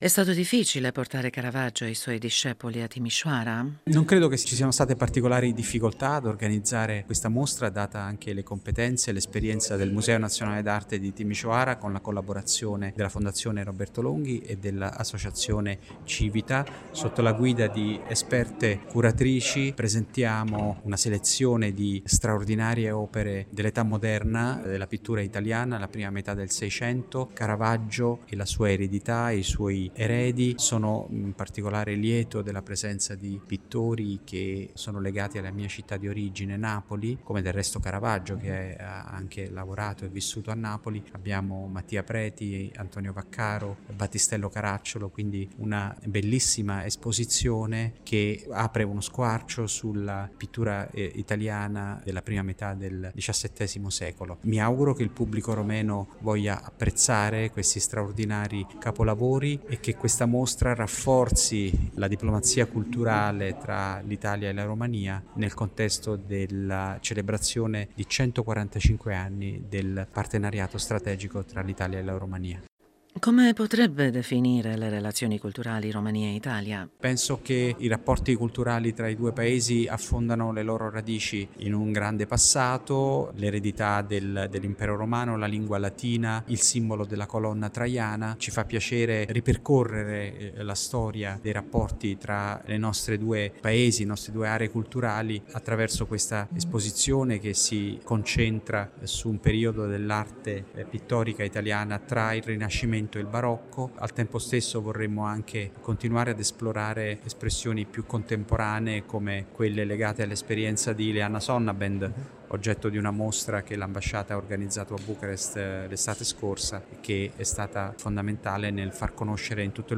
ha detto l’ambasciatore d’Italia a Bucarest, Alfredo Durante Mangoni, nell’intervista